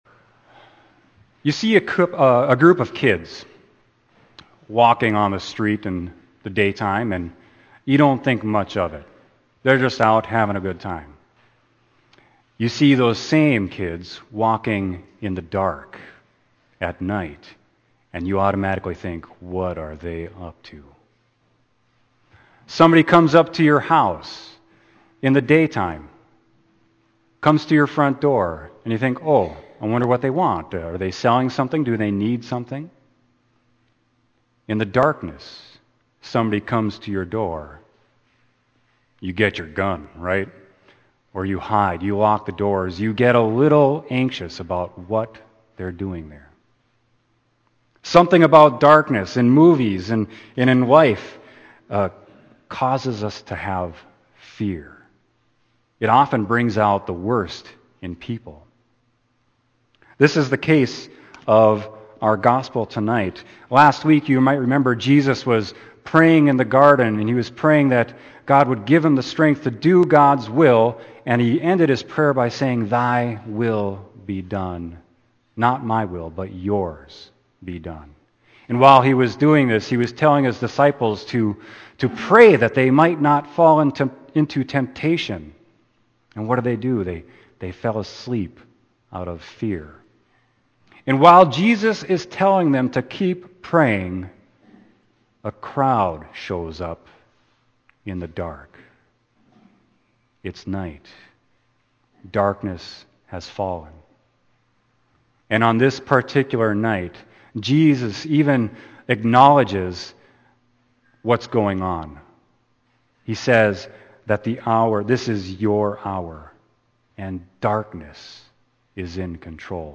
Sermon: Luke 22.47-53